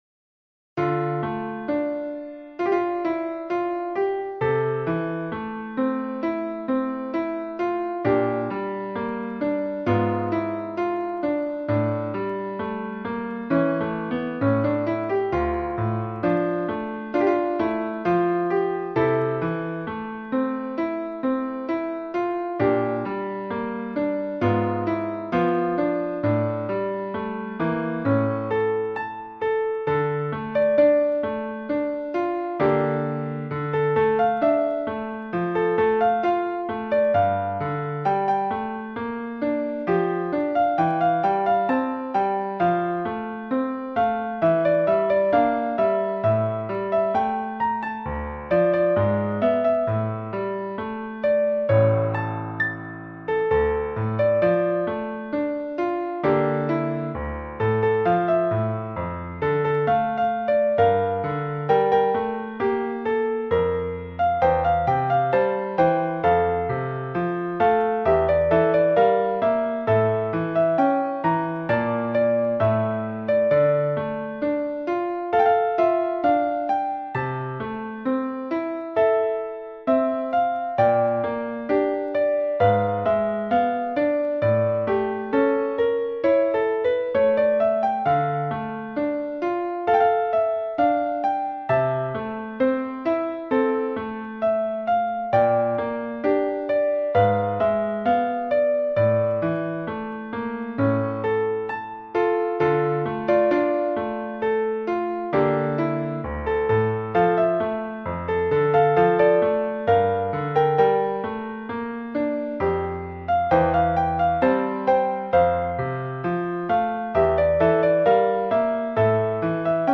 Ноты для фортепиано.